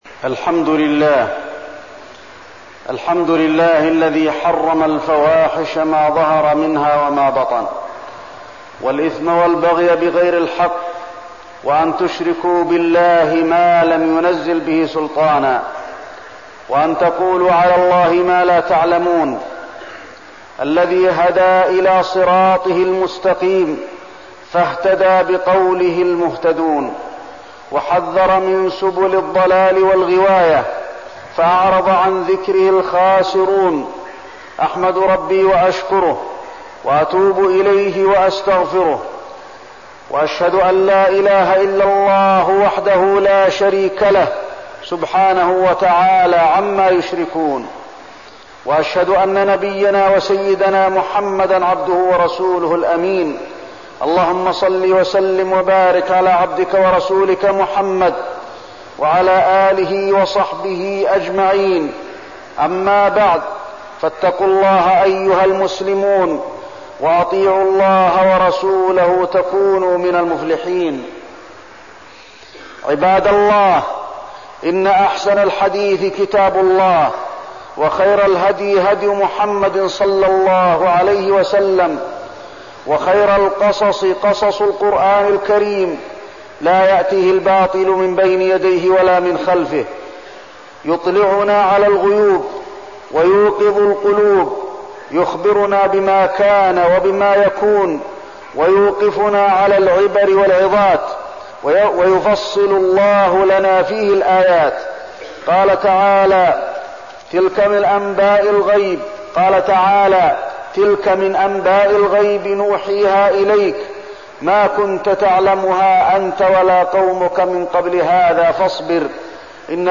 تاريخ النشر ١٥ محرم ١٤١٥ هـ المكان: المسجد النبوي الشيخ: فضيلة الشيخ د. علي بن عبدالرحمن الحذيفي فضيلة الشيخ د. علي بن عبدالرحمن الحذيفي من قصص القرآن